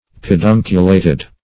Search Result for " pedunculated" : The Collaborative International Dictionary of English v.0.48: Pedunculate \Pe*dun"cu*late\, Pedunculated \Pe*dun"cu*la`ted\, a. (Biol.)
pedunculated.mp3